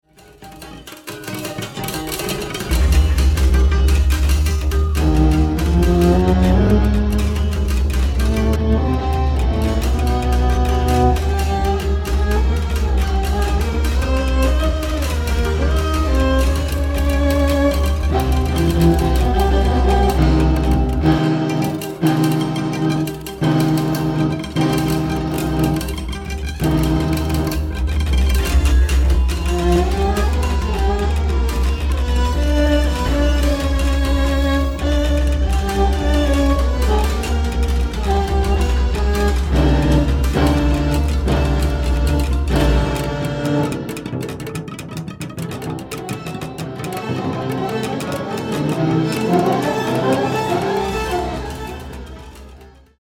na wiolonczelę i komputer